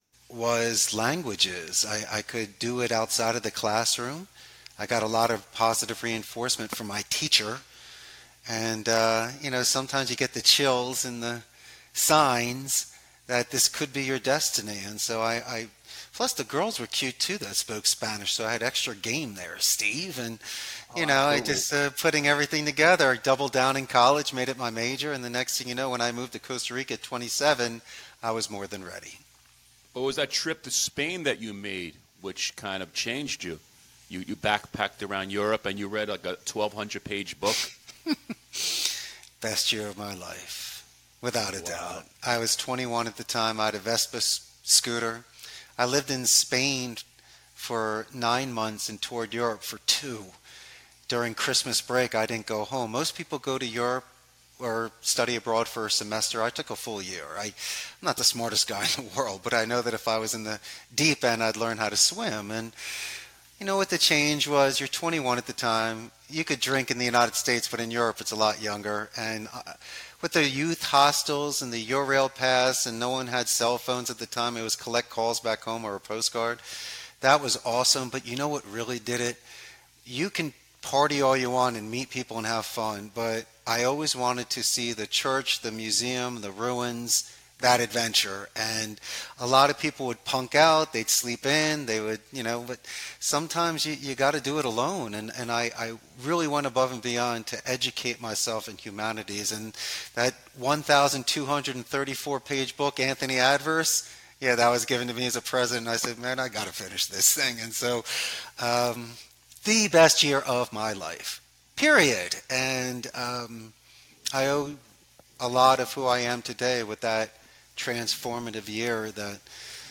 Conversation, dialogue, entertainment, news, interviews, comedy and music talk, etc.